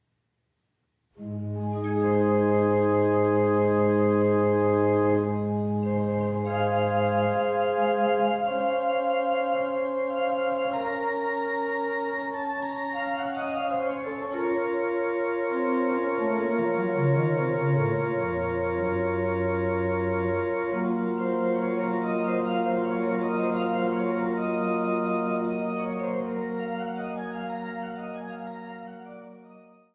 an der historischen Orgel zu Niederndodeleben
Orgel